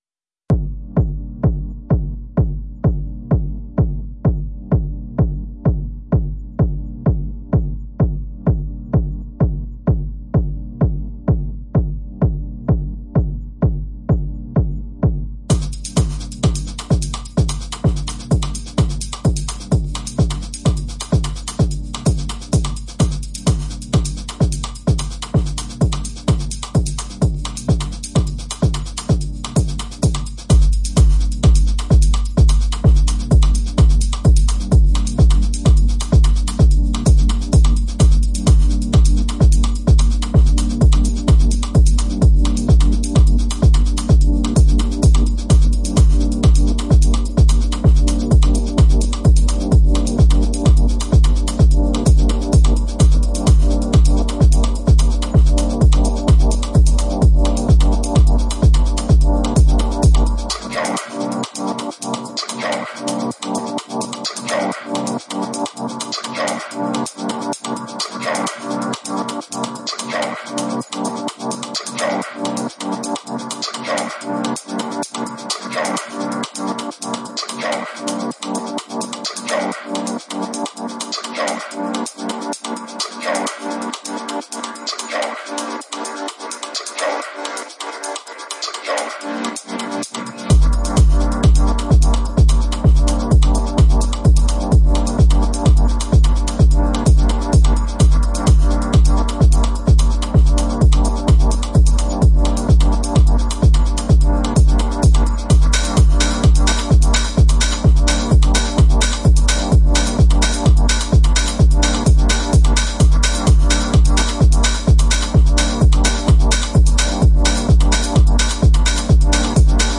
4 strong Chicago flavoured house tracks
sweet piano, dirty drums, and a raw rhythm section